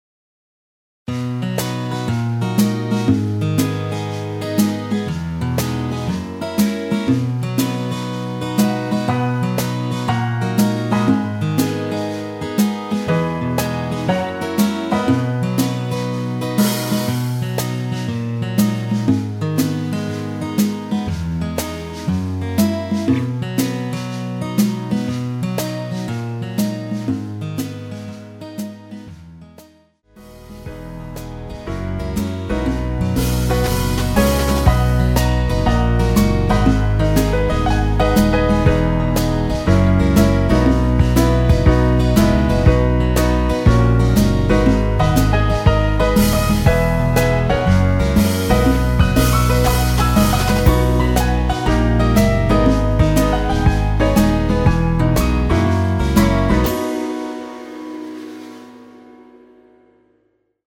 엔딩이 페이드 아웃에 너무 길어서 4마디로 엔딩을 만들었습니다.(미리듣기 참조)
원키에서(-1)내린 MR 입니다.
◈ 곡명 옆 (-1)은 반음 내림, (+1)은 반음 올림 입니다.
앞부분30초, 뒷부분30초씩 편집해서 올려 드리고 있습니다.